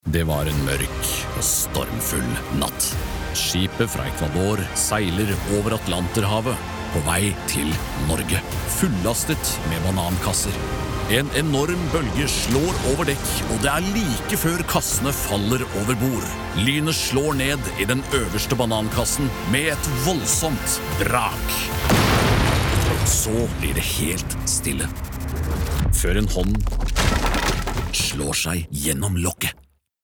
Commercial, Deep, Natural, Reliable, Friendly